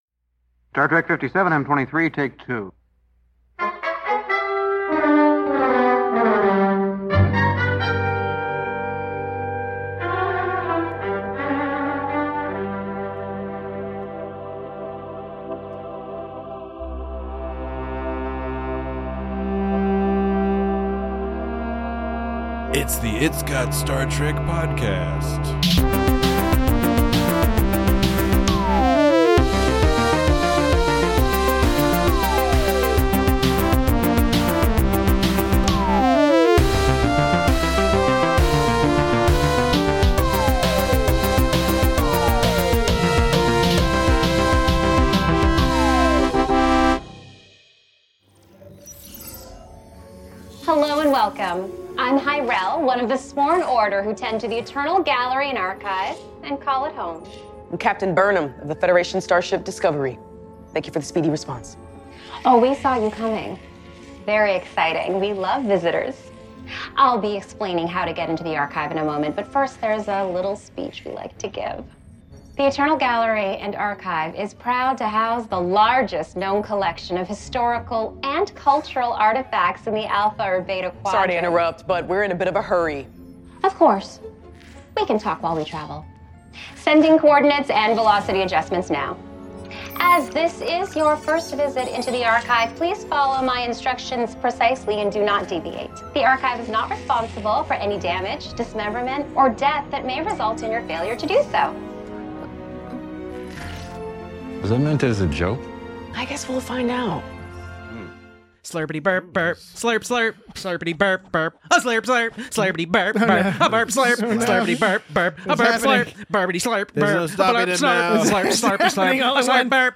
A chipper librarian inadvertently sends Burnham on a journey of self-exploration. Join your bibliophile hosts as they discuss charming and efficient world-building, debate the differences between mazes and labyrinths, and look forward to the inevitable Captain Rhys spinoff show.